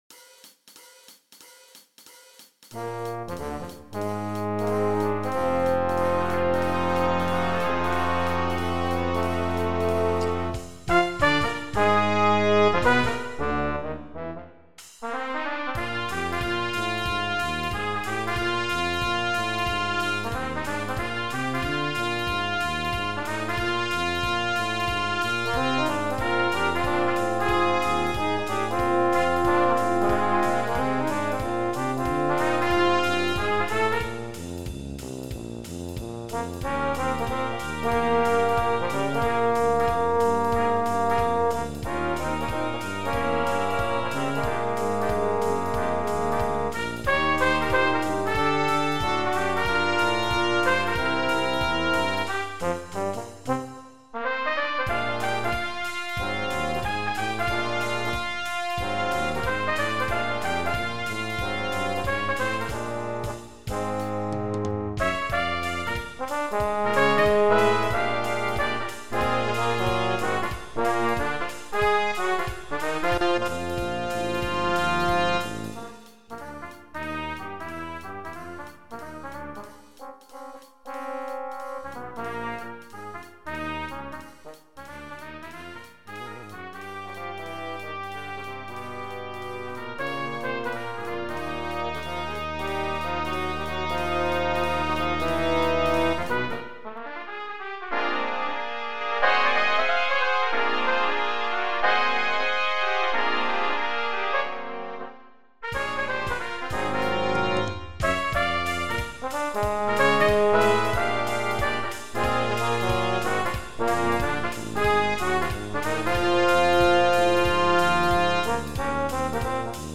Brass Quintet (optional Drum Set)